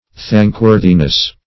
Search Result for " thankworthiness" : The Collaborative International Dictionary of English v.0.48: Thankworthiness \Thank"wor`thi*ness\, n. The quality or state of being thankworthy.
thankworthiness.mp3